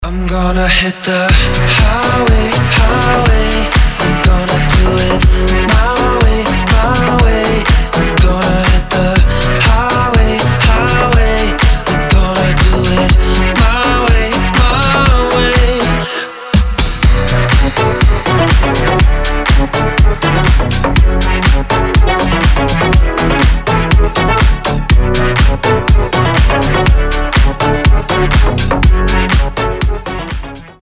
• Качество: 320, Stereo
deep house
красивый мужской голос
dance
летние